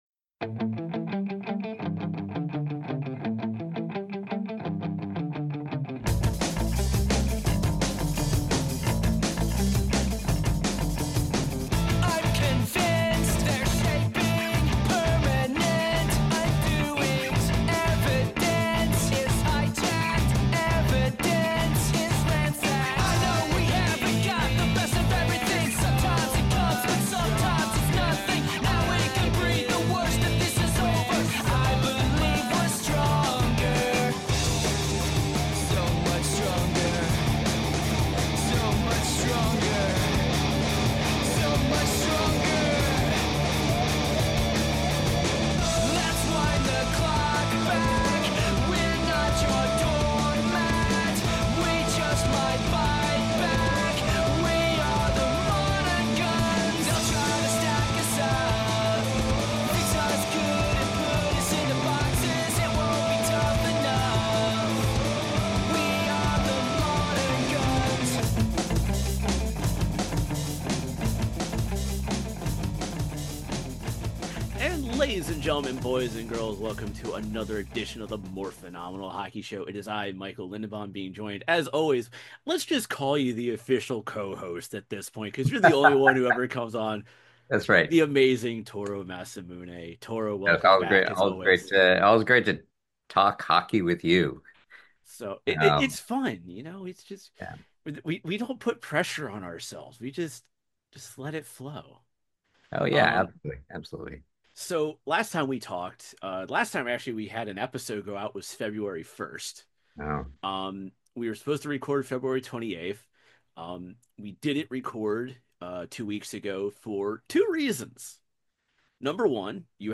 Ladies and Gentlemen welcome to The Morphinominal Hockey Show for some fun hockey conversations with actors from the Power Rangers and TMNT franchises
Opening Music (Audio Version Only)